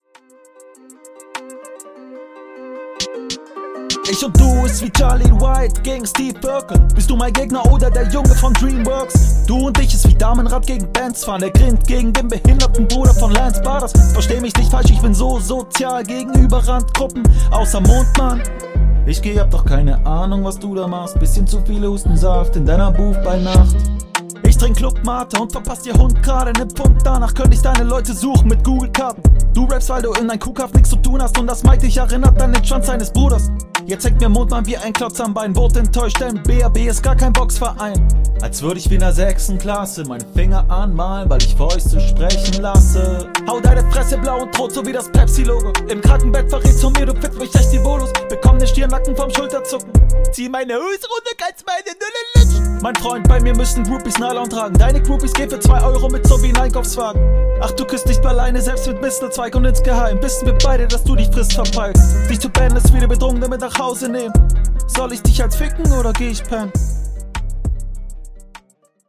Soundqualität und Flow sind einfach mega nice. Ideal auf den Beat!